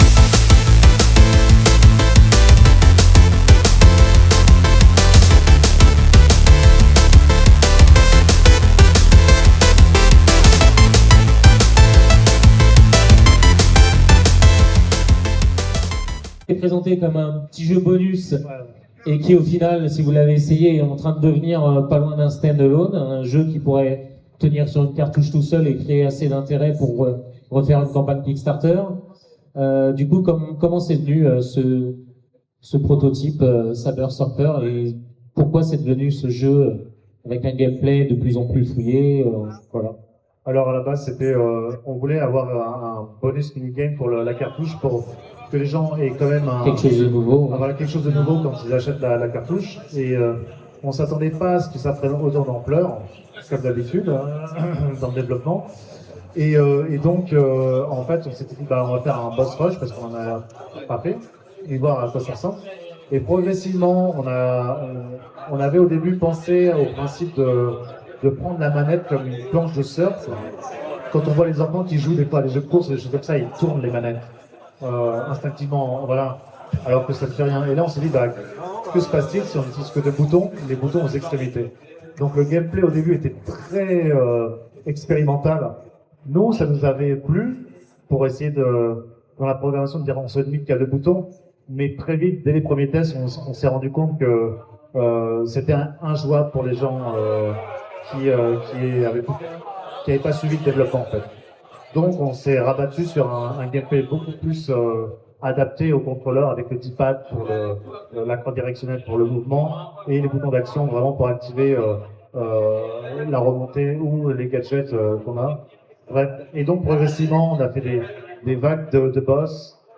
Lors de la RGC 2025 , nous avons eu l’occasion d’échanger avec les développeurs autour de Saber Surfer, un projet homebrew qui devait à l’origine être un simple mini-jeu bonus. Dans cette interview, ils reviennent sur la genèse du projet, son évolution vers un jeu de plus en plus complet, et les nombreux défis liés au développement